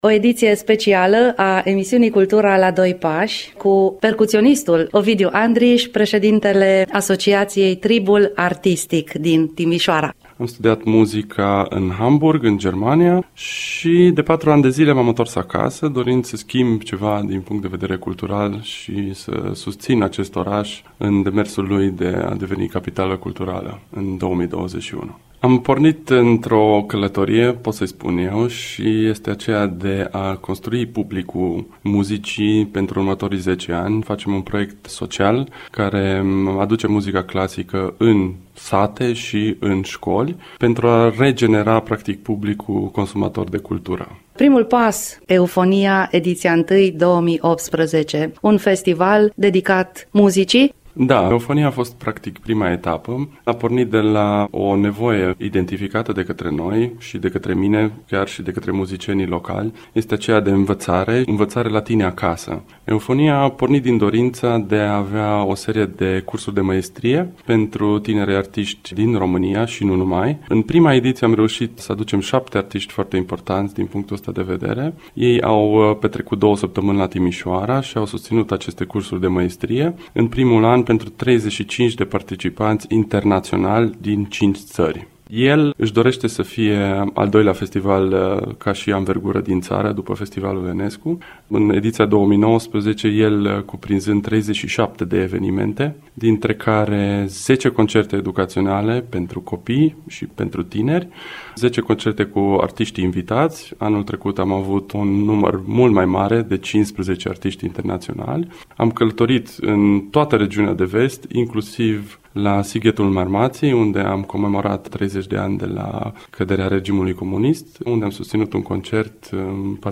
INTERVIU/ Proiectele Asociației Tribul Artistic - Radio România Timișoara